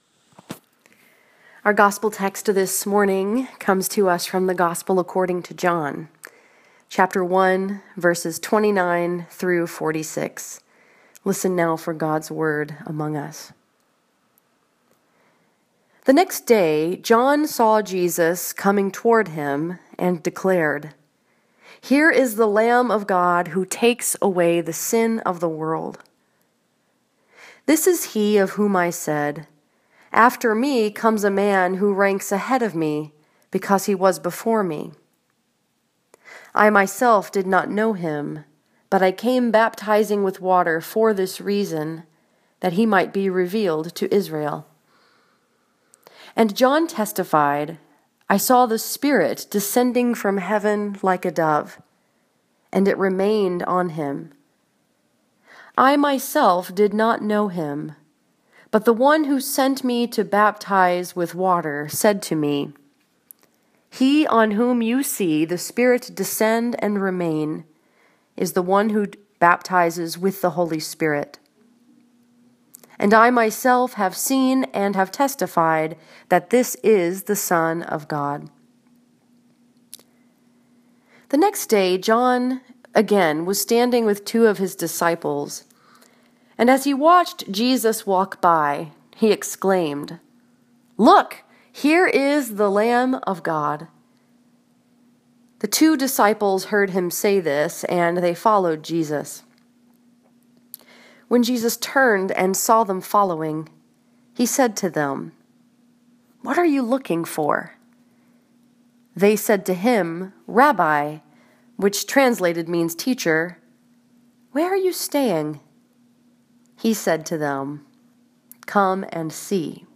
[Public Domain] This sermon was preached at Northside Presbyterian Church in Ann Arbor, Michigan and was focused upon John 1:29-46.